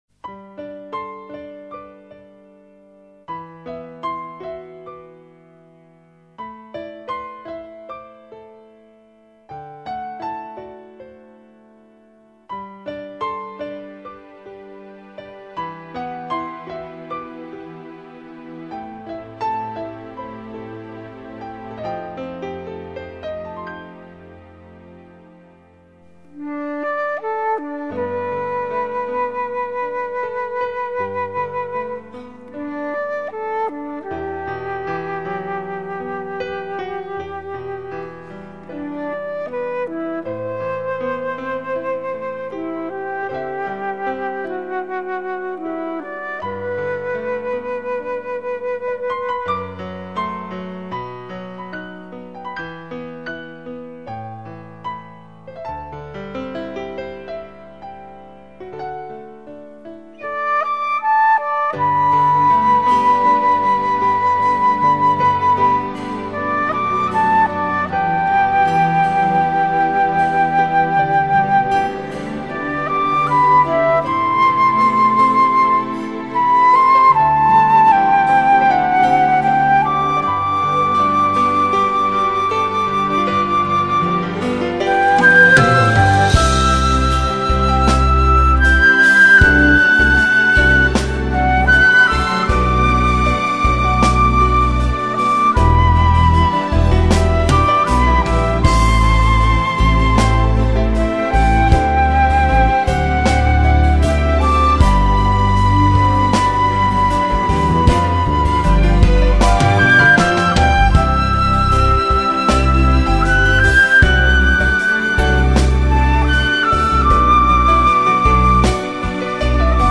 0189-长笛 短笛名曲未知的梦.mp3